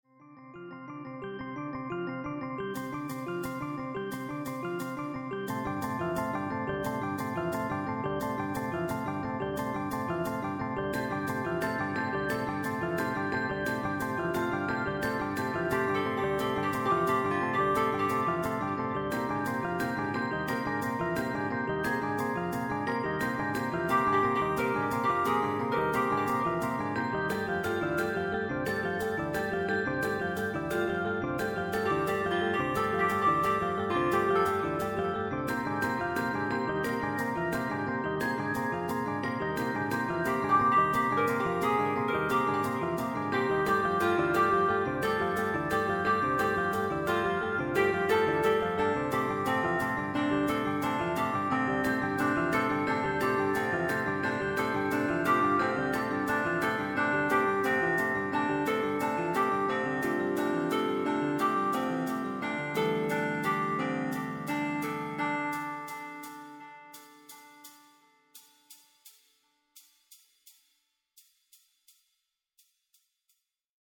Composizioni per coro di voci bianche:
Scarica la base - mp3 1,12 Mb